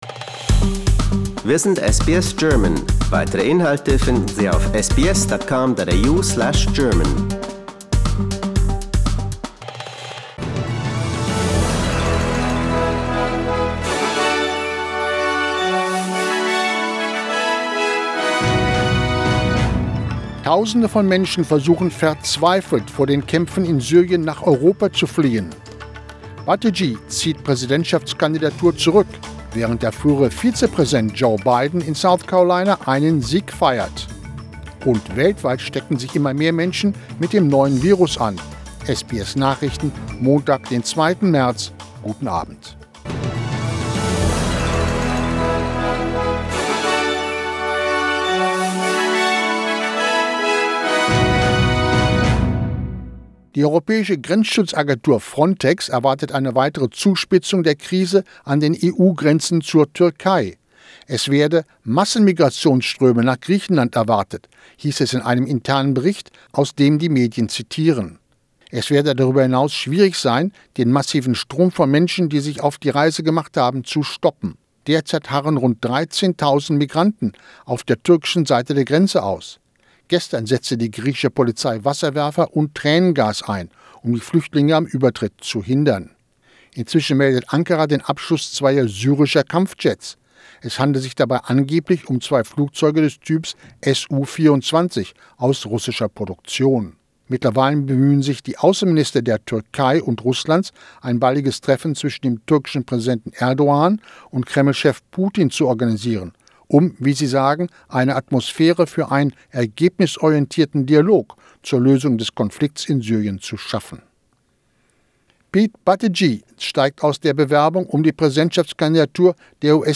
SBS Nachrichten, Montag 02.03.20